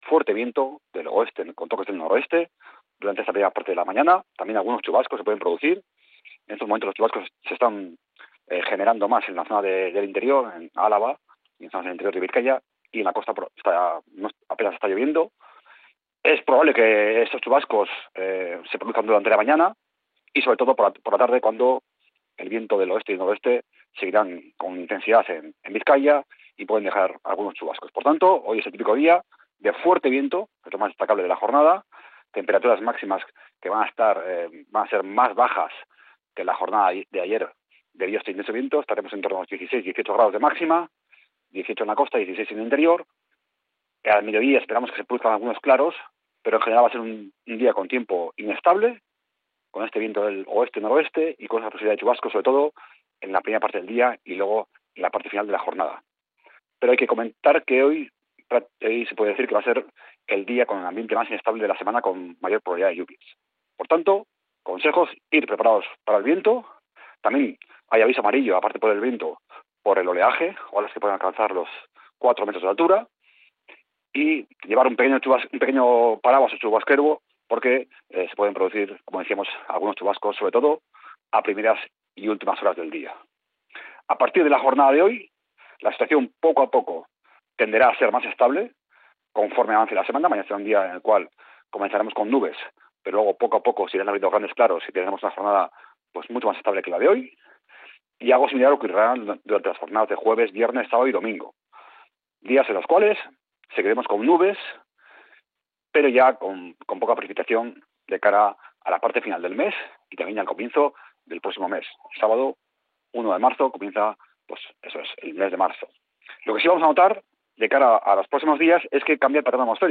El pronóstico del tiempo en Bizkaia para este 25 de febrero